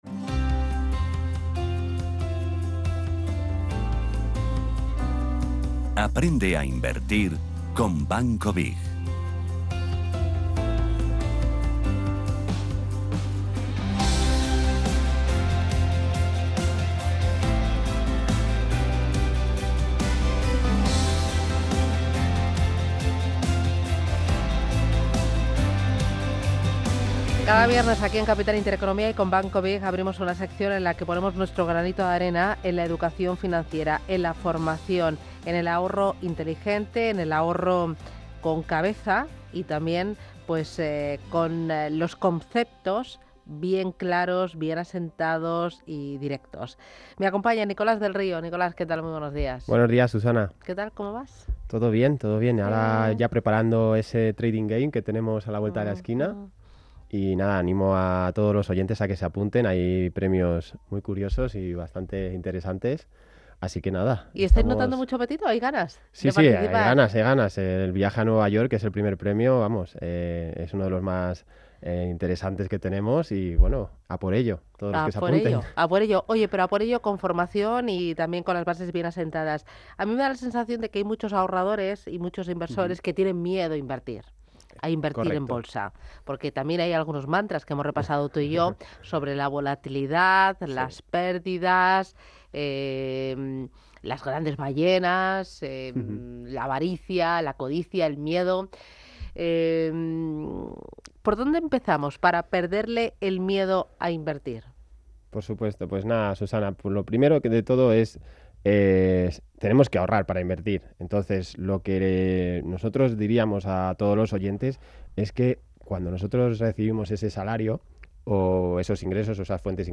Descubre las respuestas a estas preguntas y mucho más en la conversación mantenida durante el programa.